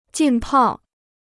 浸泡 (jìn pào): to steep; to soak.